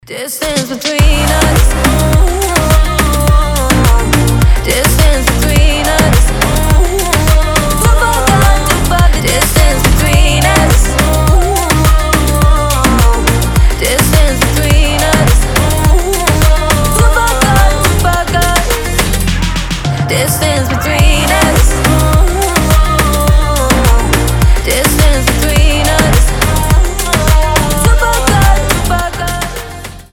• Качество: 320, Stereo
восточные мотивы
EDM
Dance Pop
красивый женский голос
house